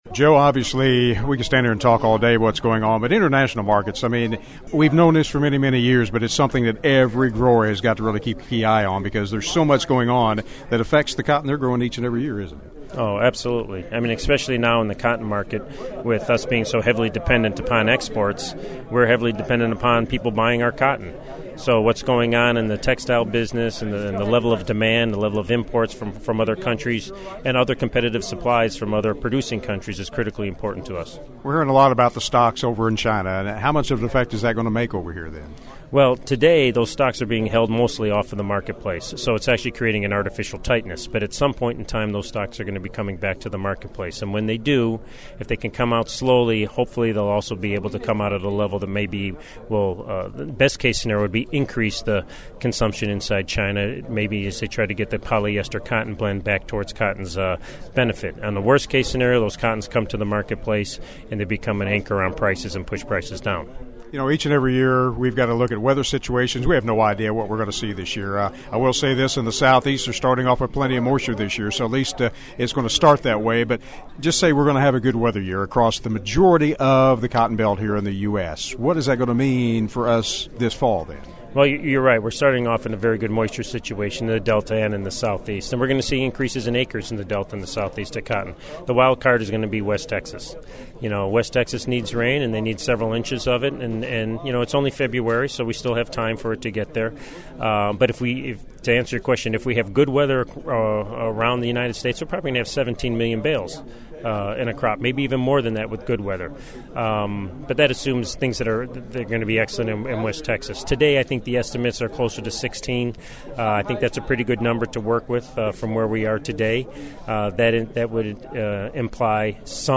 There are a variety of seminars and forums taking place today and tomorrow during the 62nd annual Farm & Gin Show in Memphis.